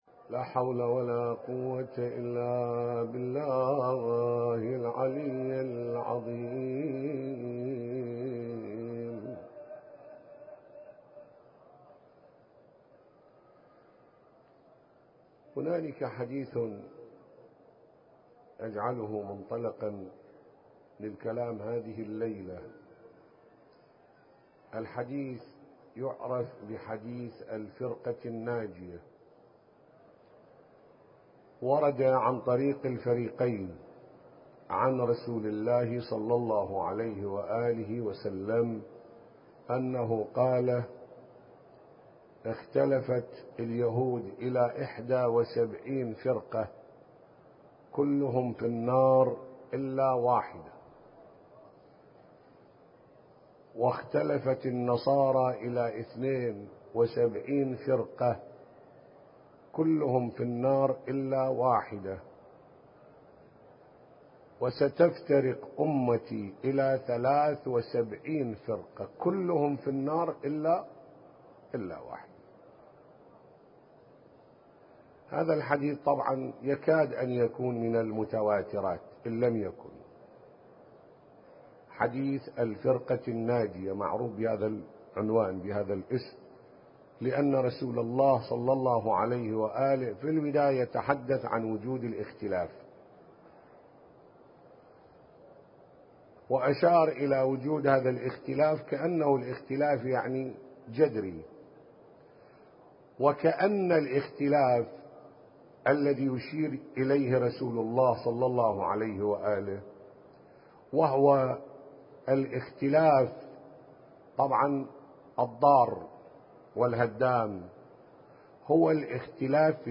المنبر الحسيني - العتبة الحسينية المقدسة